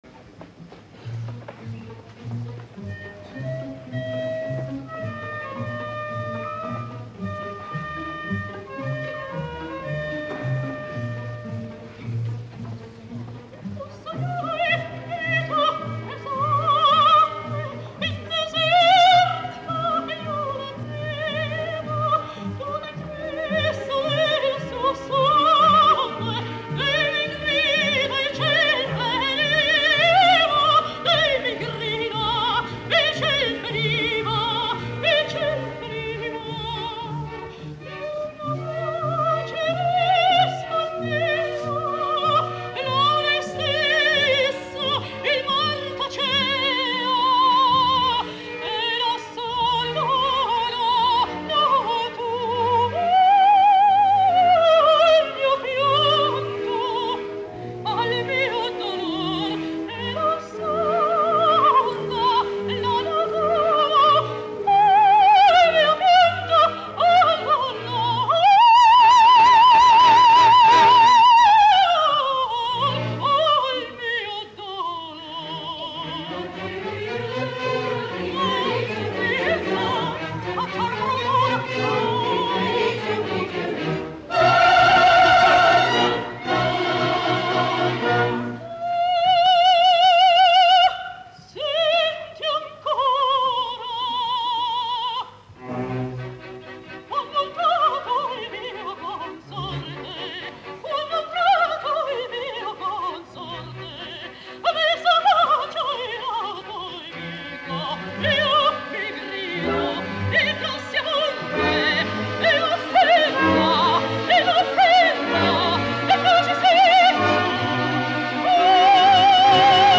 I ara tal i com ho va cantar Montserrat Caballé al Liceu, l’any 1971